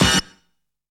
CHOC STAB.wav